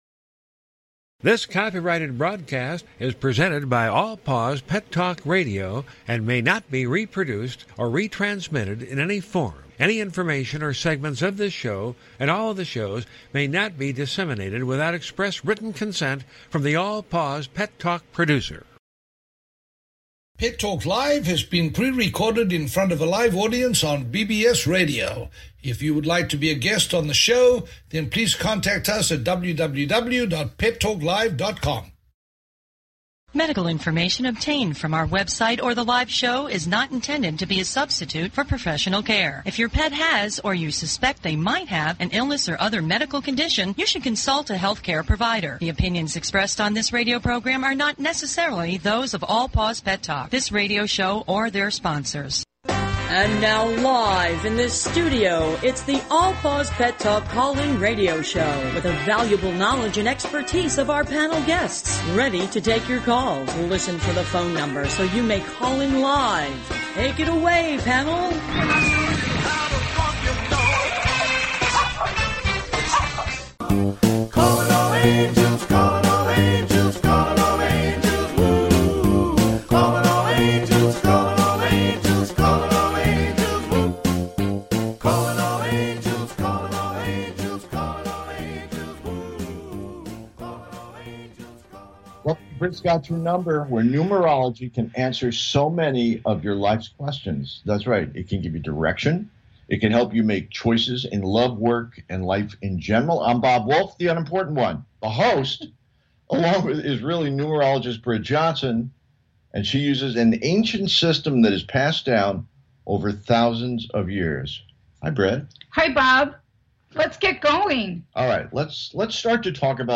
Free audience participation and free call-in numerology readings.